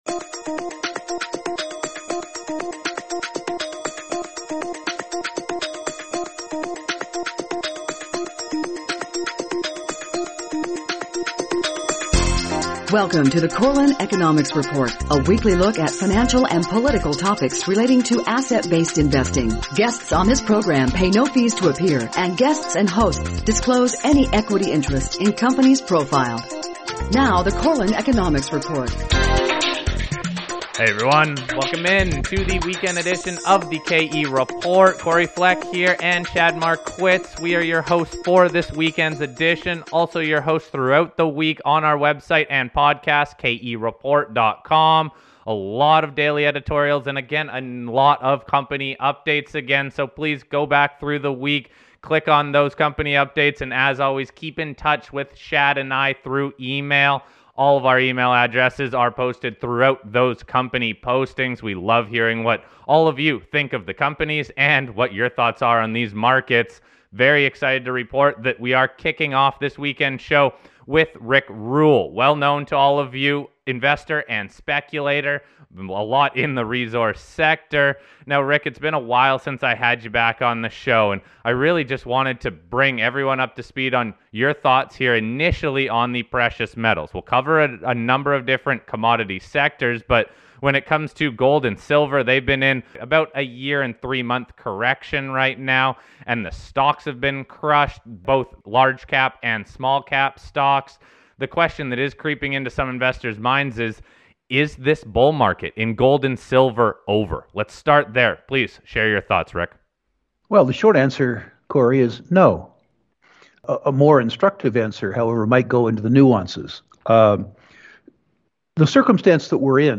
Exclusive Company Interviews This Week